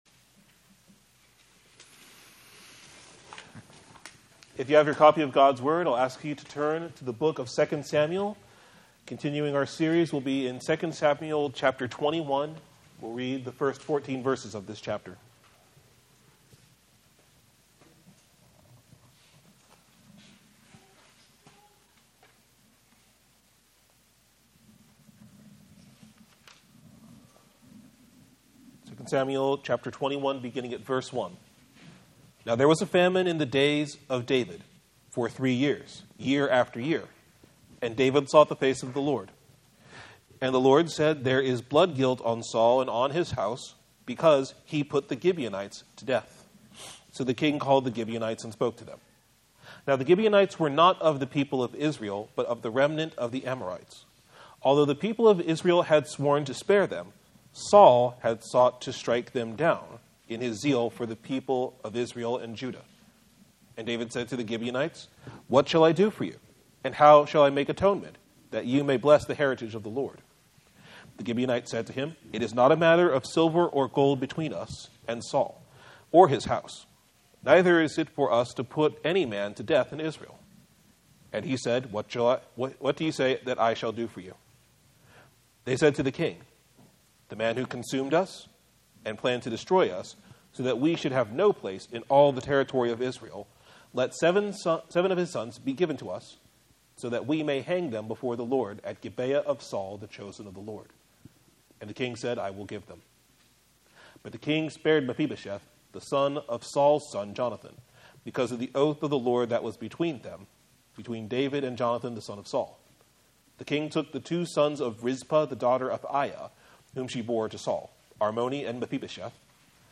Sermon Text: 2 Samuel 21:1-14 Theme: The guilt of the sins of God’s people demands that blood be shed before the wrath and curse of the Lord can be removed from upon them.